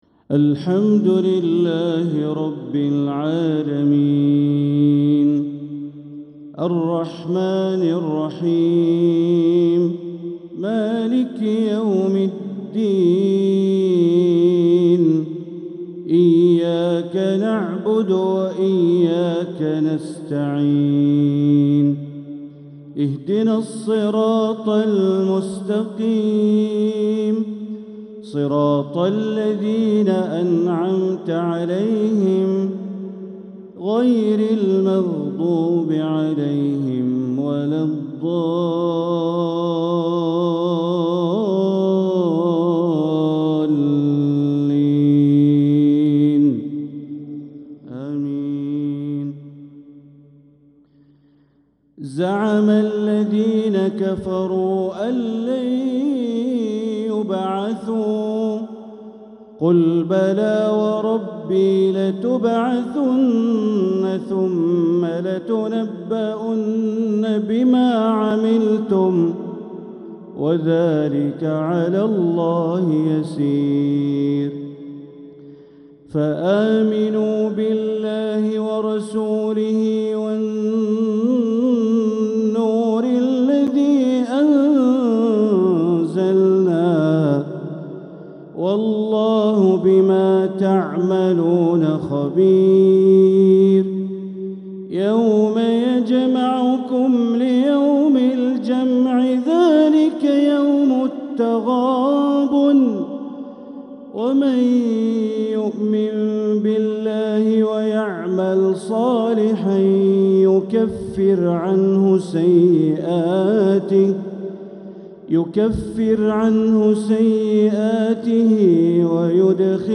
تلاوة من سورتي التغابن و الطلاق | مغرب الإثنين 9-5-1446هـ > 1446هـ > الفروض - تلاوات بندر بليلة